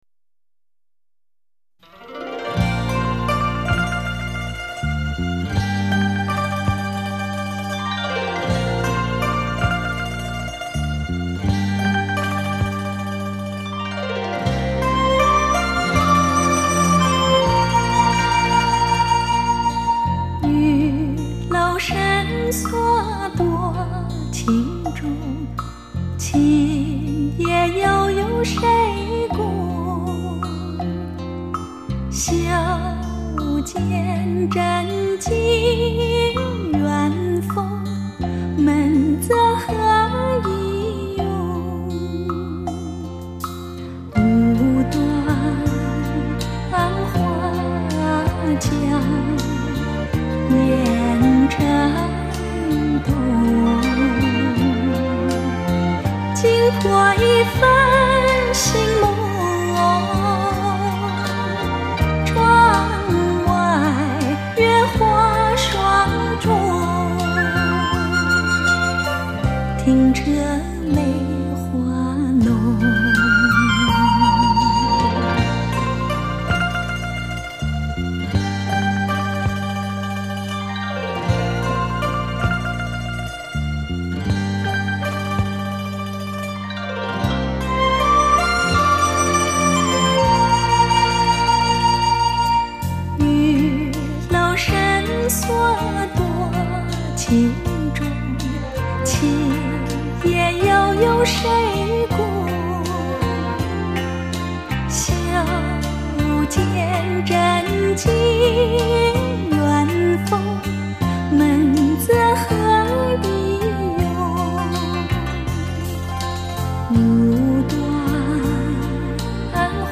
该专辑以原版录音再经过最新技术作后期
表现得更加圆润亮丽